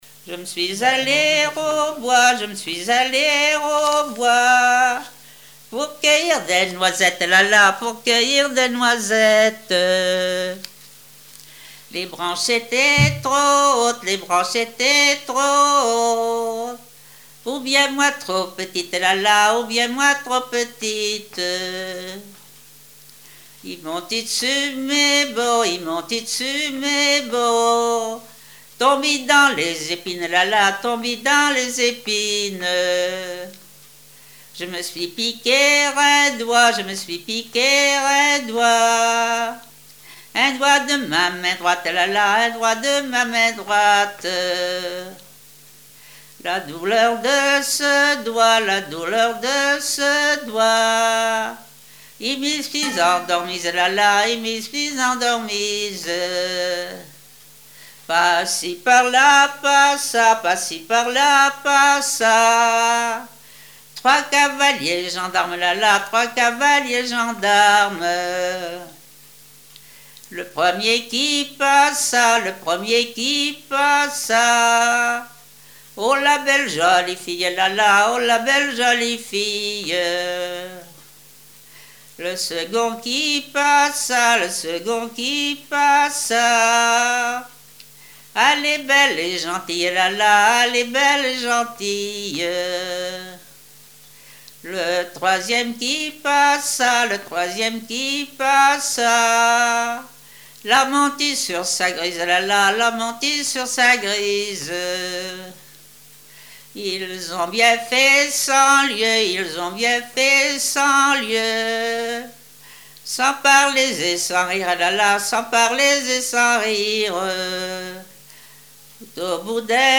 chansons traditionnelles et témoignages
Pièce musicale inédite